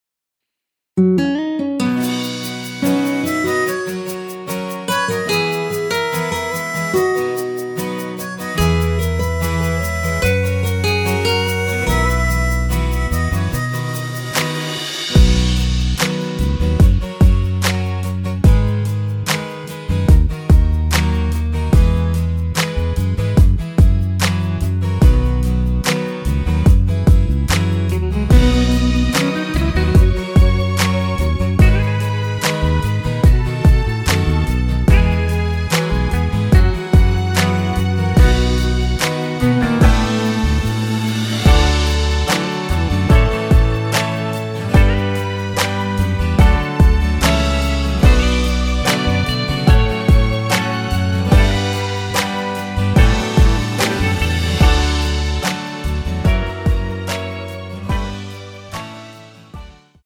대부분의 남성분이 부르실수 있는 키로 제작 하였습니다.
원키에서(-10)내린 MR입니다.
앞부분30초, 뒷부분30초씩 편집해서 올려 드리고 있습니다.
중간에 음이 끈어지고 다시 나오는 이유는